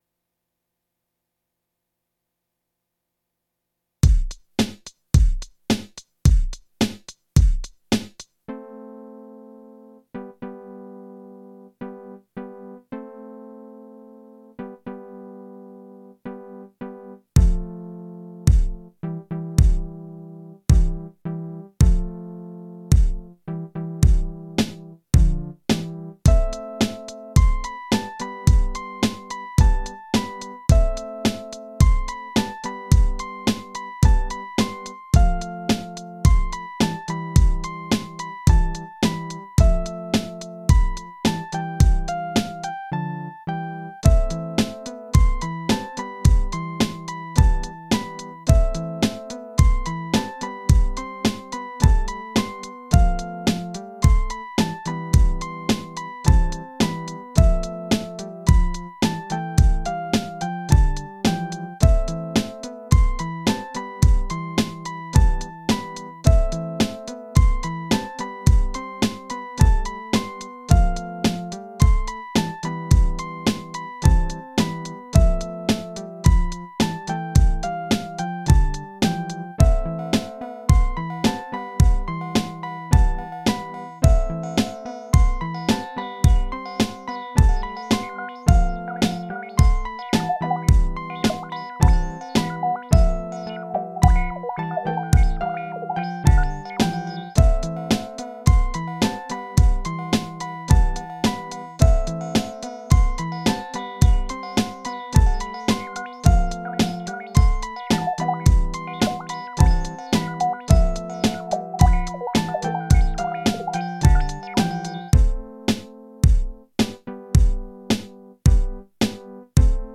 Here’s the first beat I made when I got the DR202 again in 2022: